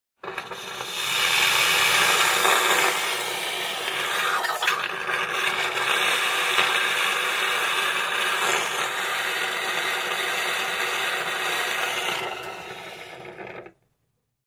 Звук слюноотсоса